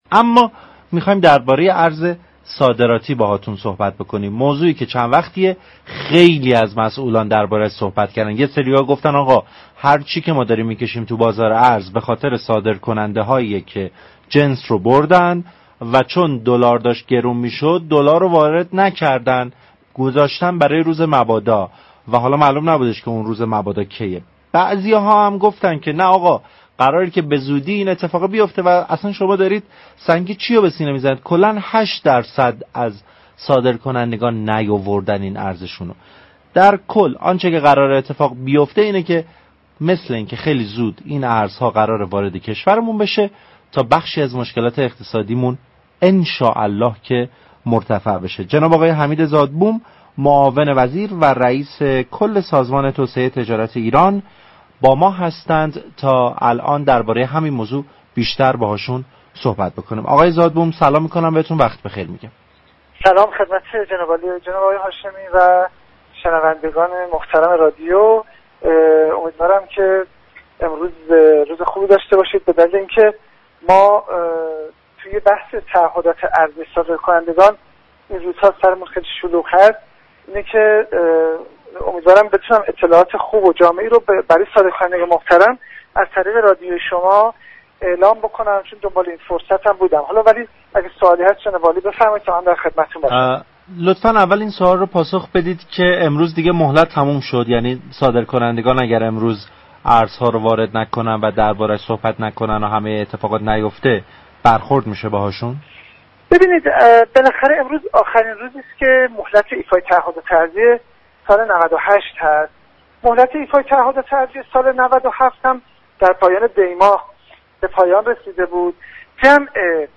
حمید زادبوم، معاون وزیر و رئیس كل سازمان توسعه تجارت ایران در گفتگو با